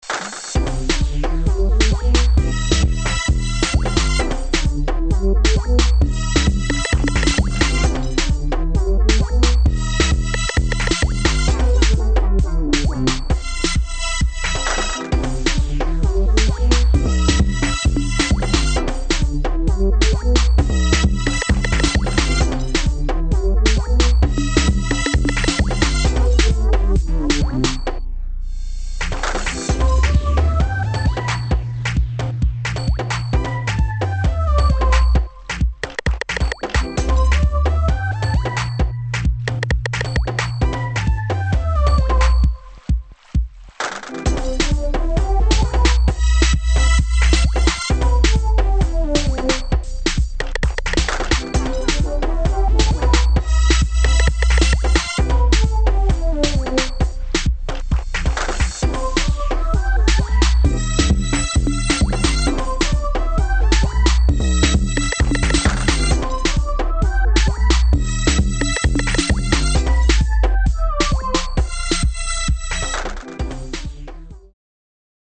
[ BASS | UK GARAGE ]